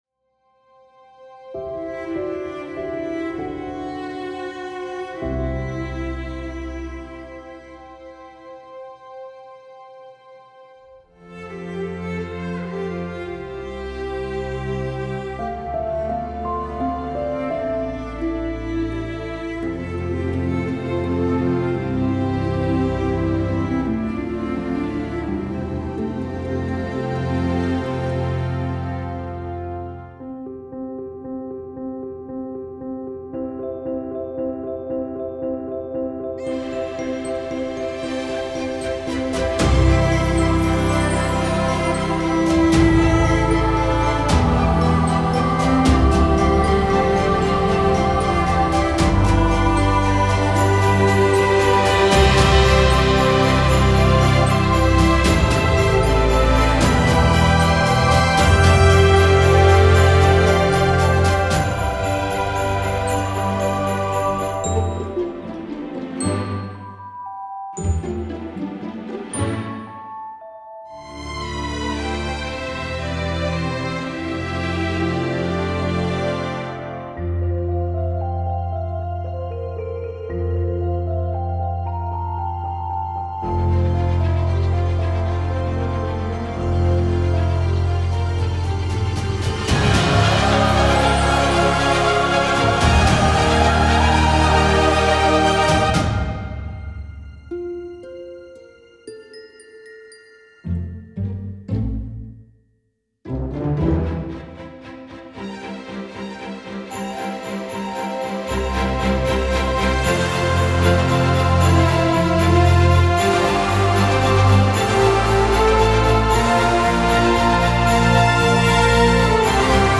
Genre: filmscore.